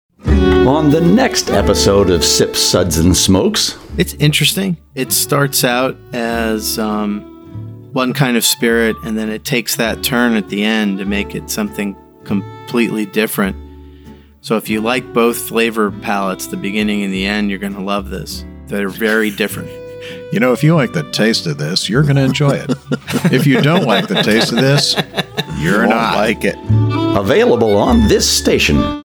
192kbps Mono